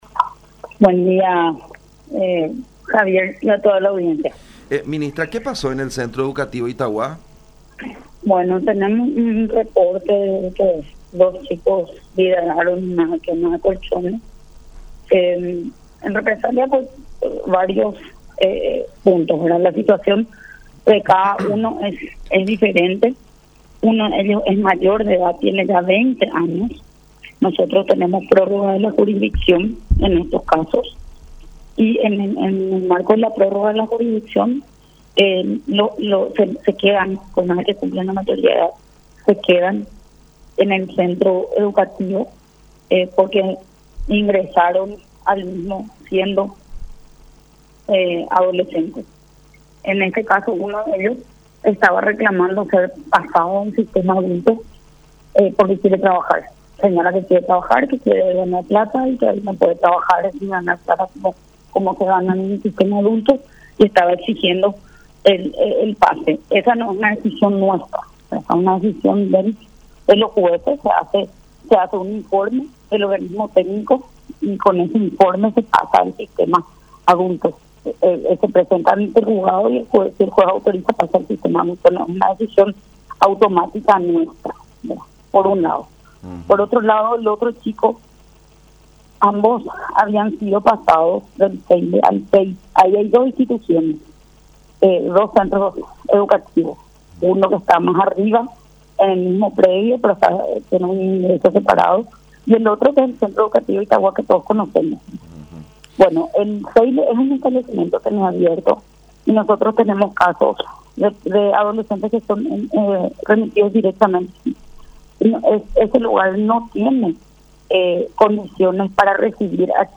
“Tuvimos un incidente dentro del Centro Educativo de Itauguá, que fue provocado por dos jóvenes en reclamo de algunos puntos. Uno de ellos pidió ser trasladado al sistema de adultos, porque quiere trabajar”, detalló la ministra de Justicia, Cecilia Pérez, en conversación con La Unión, indicando que el hecho sucedió en el Pabellón La Esperanza.